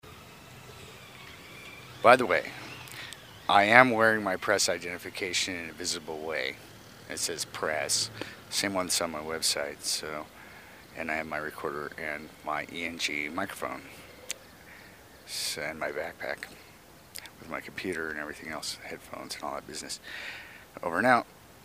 Live audio: Missoula cops cite beggar
The two officers involved in the sting were riding motorcycles.